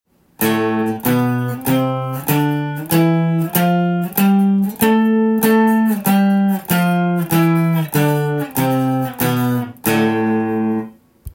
「５弦オクターブ」
５弦を使用するオクターブ奏法では５弦に人差し指を置き
人差し指腹で開放弦が鳴らないようにミュートしていきます。